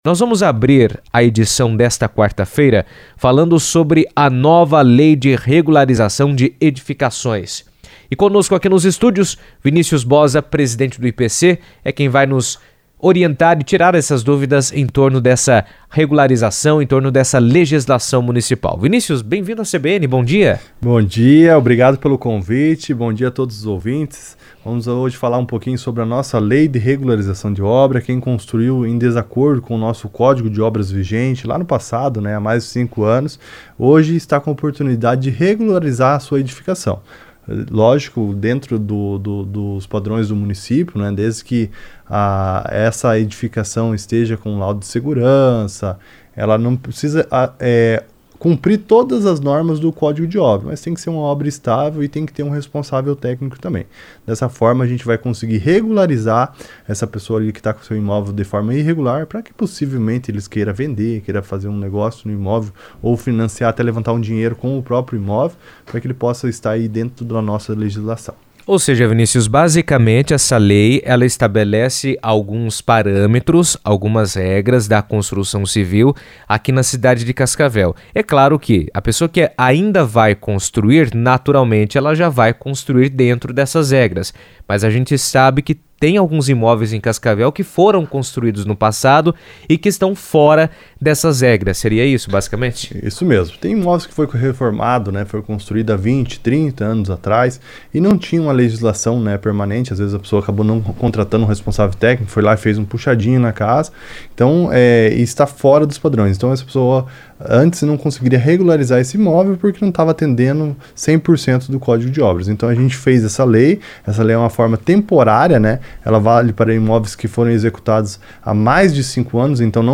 O Instituto de Planejamento de Cascavel (IPC) tem orientado a população sobre a Lei de Regularização de Edificações, que estabelece critérios e procedimentos para a adequação de imóveis construídos em desacordo com a legislação urbanística. Em entrevista à CBN Cascavel, o presidente do IPC, Vinicius Boza, explicou os principais pontos da lei, destacando quem pode se beneficiar da regularização, os prazos previstos e a importância da medida para garantir segurança jurídica aos proprietários e a organização do espaço urbano.